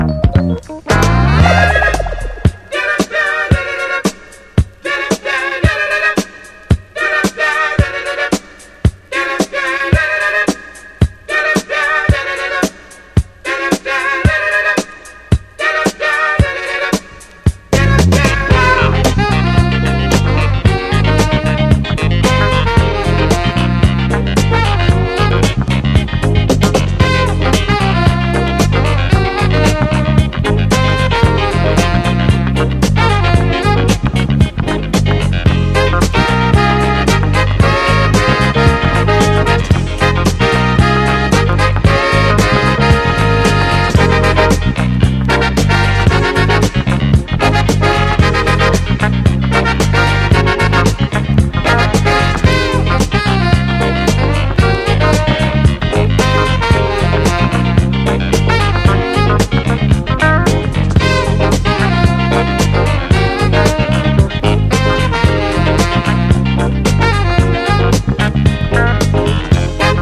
GHETTO DISCO | FUNKY BEATS
SOUL / SOUL / 70'S～ / DISCO FUNK / DISCO / DRUM BREAK